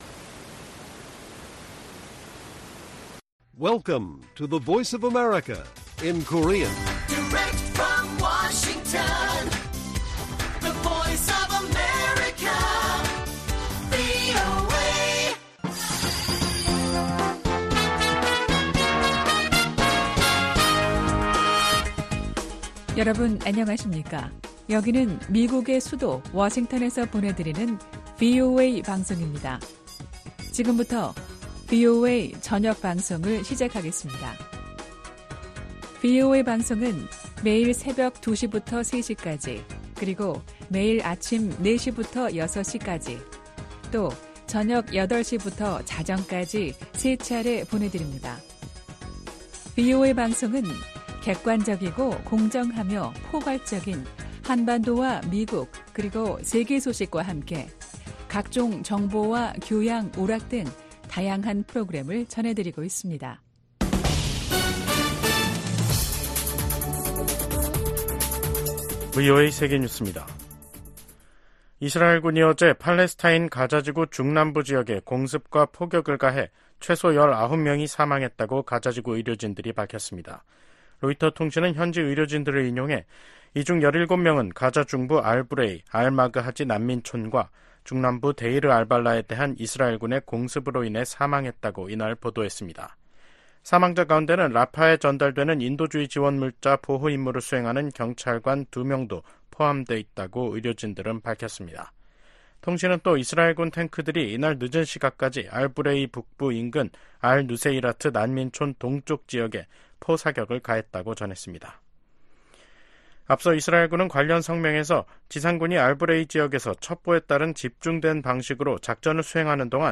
VOA 한국어 간판 뉴스 프로그램 '뉴스 투데이', 2024년 6월 6일 1부 방송입니다. 미국, 한국, 일본이 국제원자력기구 IAEA 정기 이사회에서 북한과 러시아의 군사 협력 확대를 비판하며 즉각 중단할 것을 한목소리로 촉구했습니다. 백악관이 북한 정권의 대남 오물풍선 살포 등 도발과 관련해 큰 우려를 가지고 주시하고 있다며 평양이 불필요한 행동을 계속하고 있다고 비판했습니다.